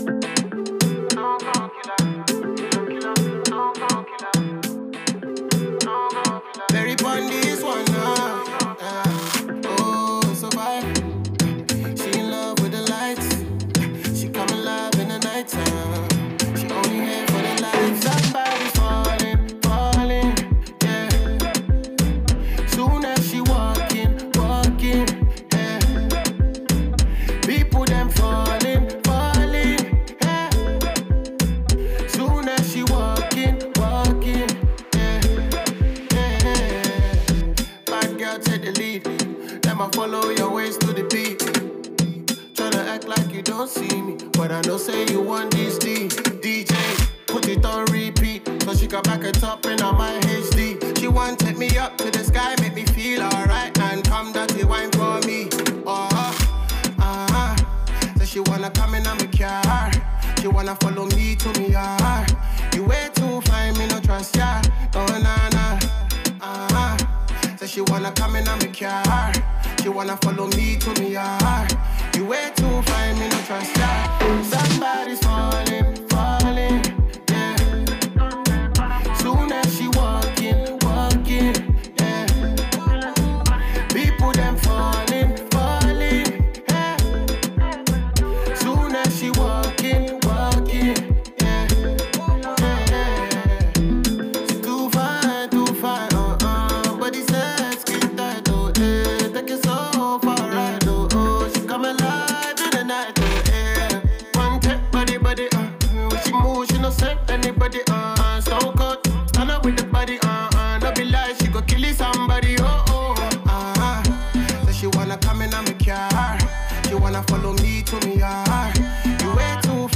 He puts this record on a fast mode to set parties on fire.